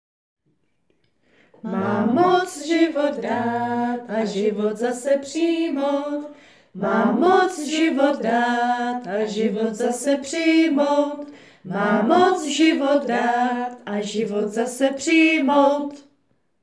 náhled titulu - Znělka 4. neděle Velikonoční B Popis: Záznam znělky pro Boží hod velikonoční, nazpívaly tety z FATYMu.